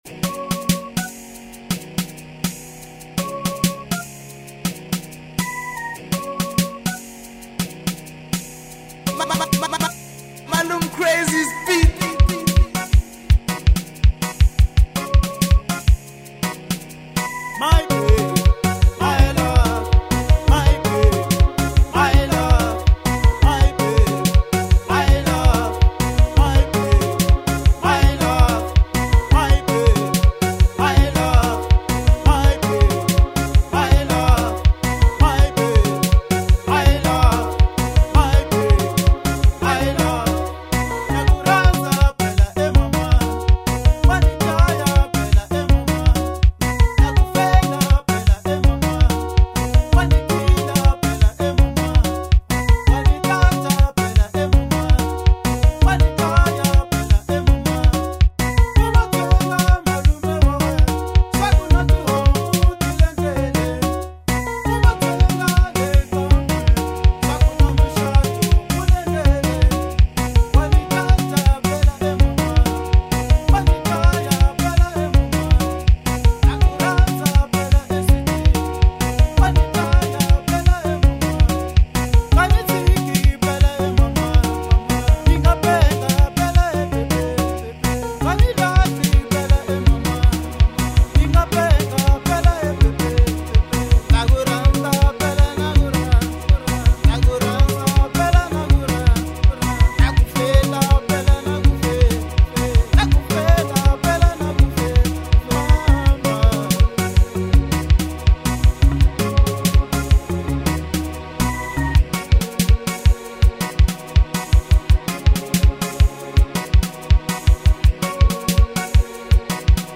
05:23 Genre : Xitsonga Size